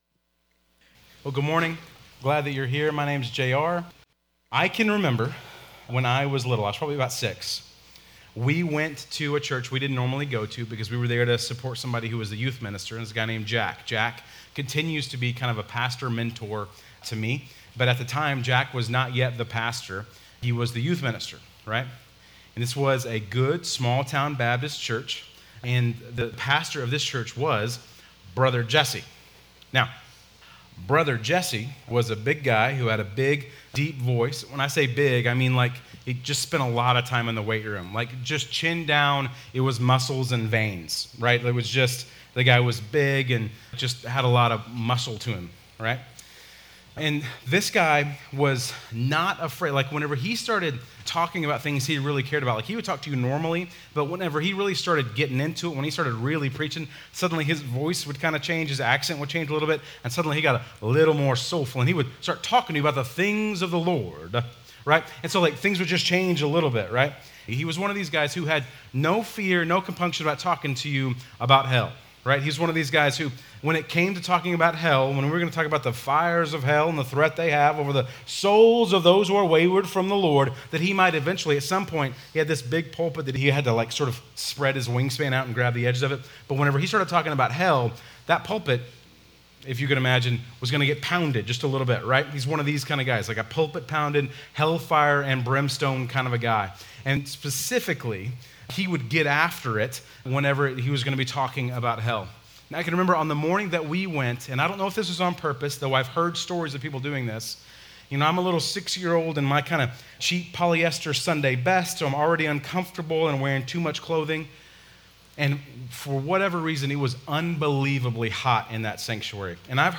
Bible Text: Matthew 3:10-17 | Preacher